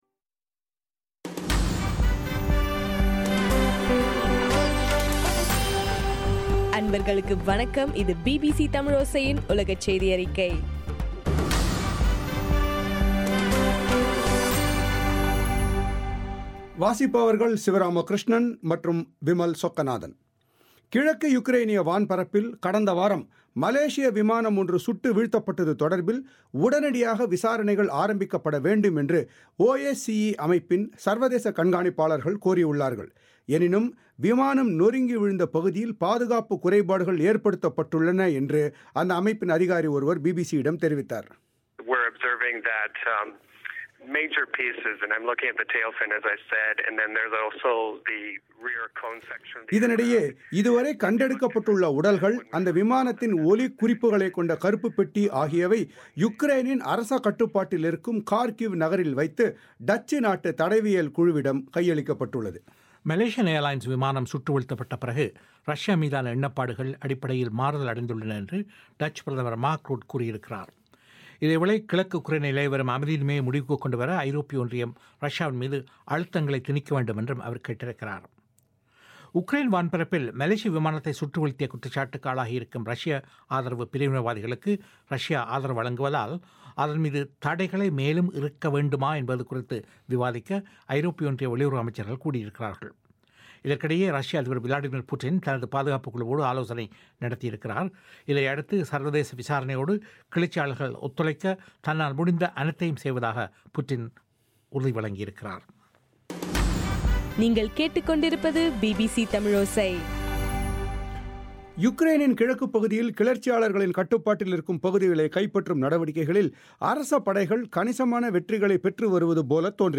இன்றைய ( ஜூலை 22) பிபிசி தமிழோசை உலகச் செய்தியறிக்கை